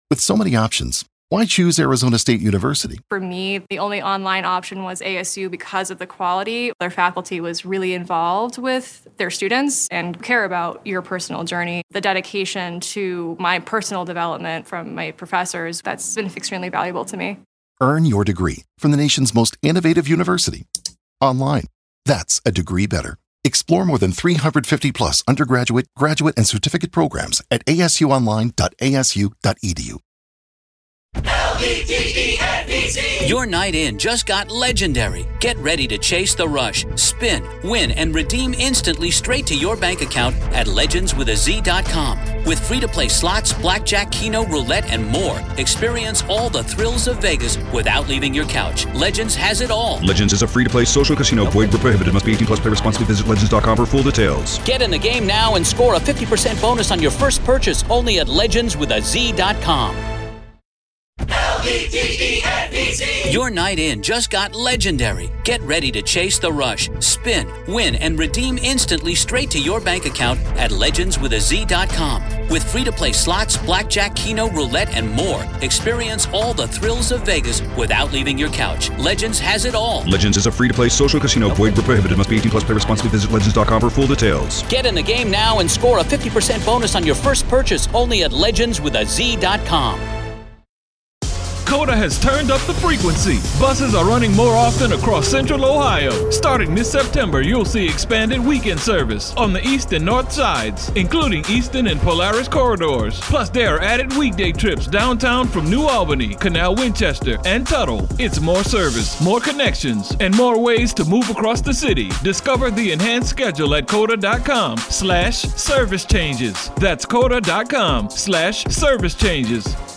former prosecutor and defense attorney